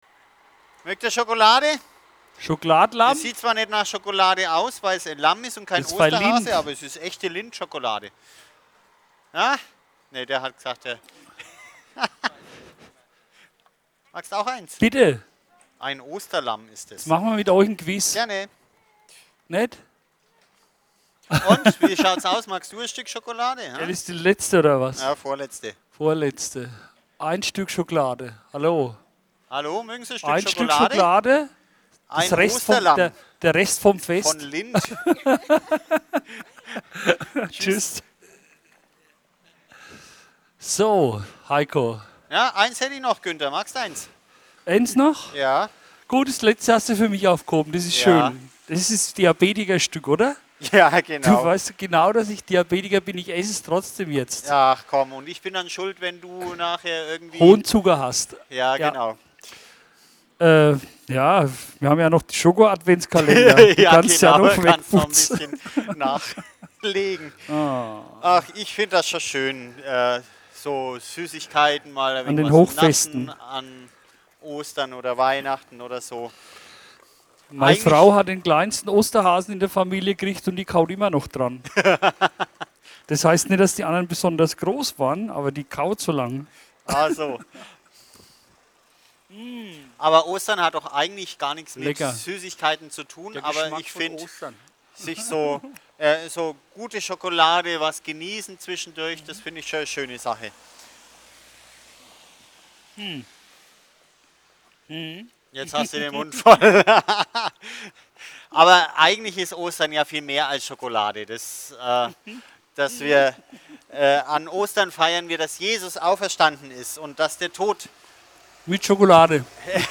Kurze Impulse zum Nachdenken fürs Wochenende.